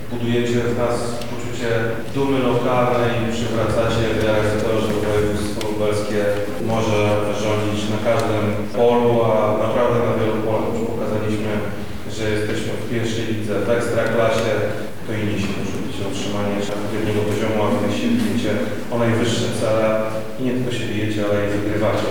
Krzysztof Komorski – zwraca się do grupy Wojewoda Lubelski, Krzysztof Komorski.